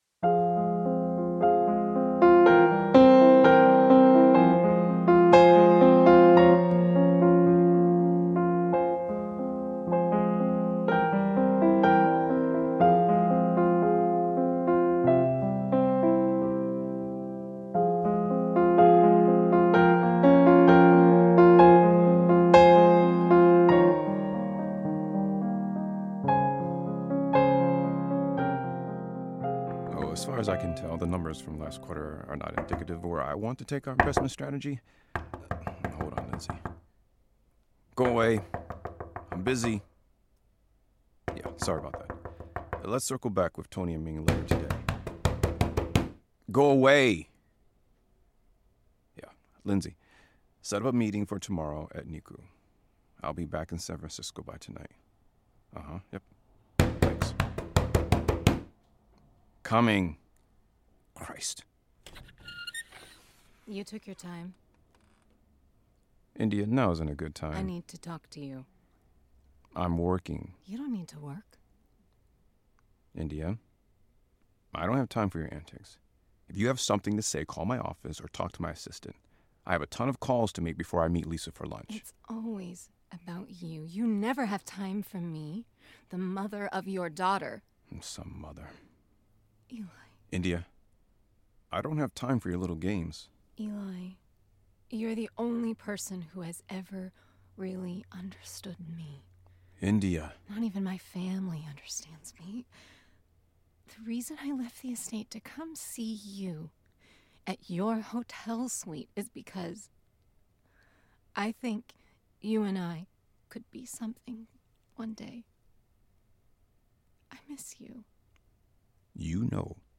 In the pilot episode of Kingsport, a new audio soap opera, the troubled and dark India Montgomery, makes a request of Eli Davenport.